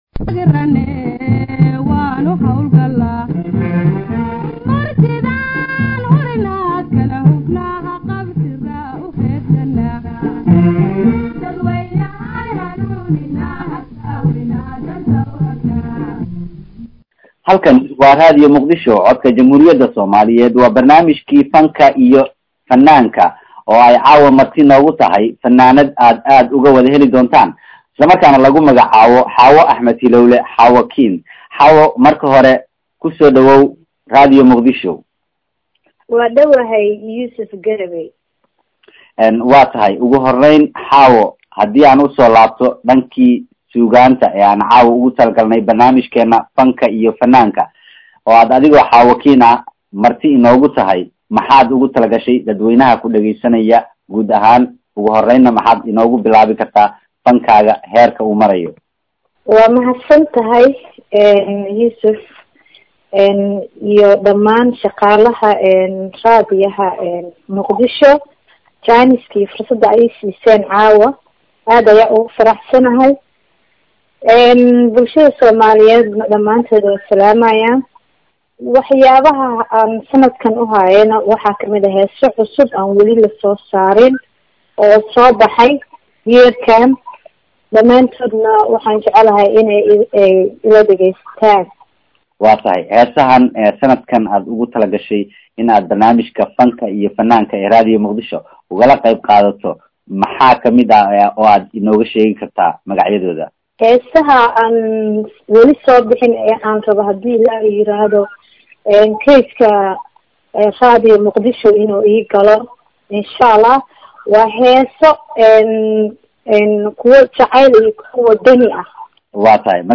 ayaa khadka telfonka ku wareystay